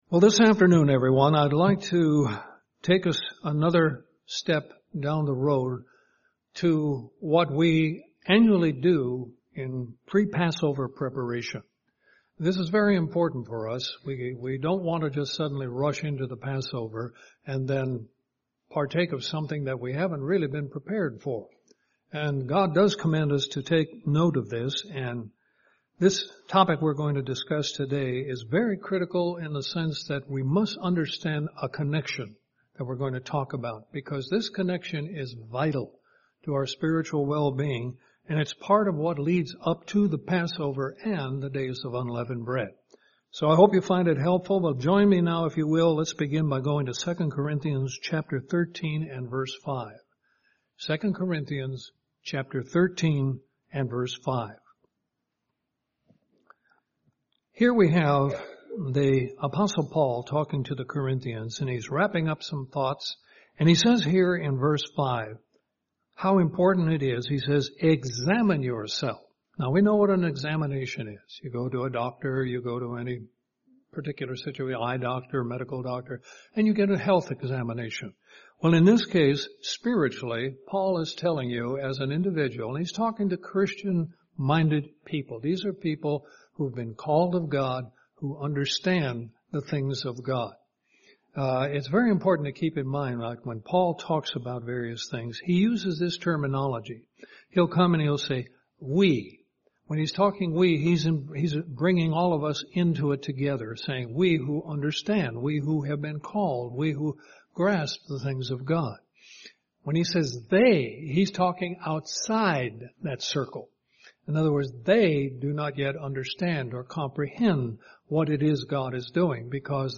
Given in Central Georgia Columbus, GA
UCG Sermon Studying the bible?